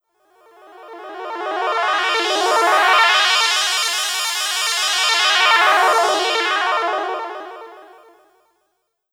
Passing Wasp.wav